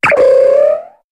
Cri de Karaclée dans Pokémon HOME.